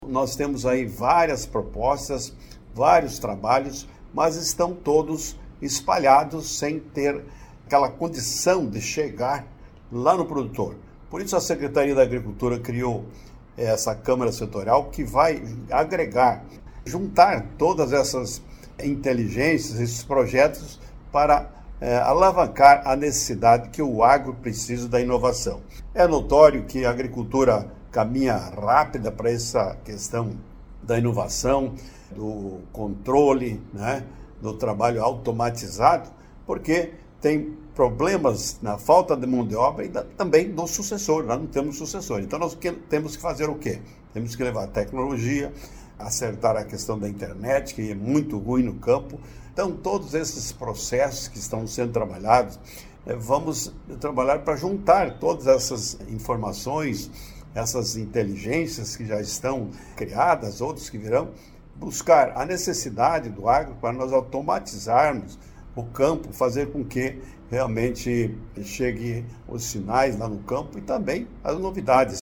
Segundo o secretário de Estado da Agricultura Pecuária, Valdir Colatto, a Câmara Setorial ouve o setor e junta inteligências para alavancar o agro catarinense: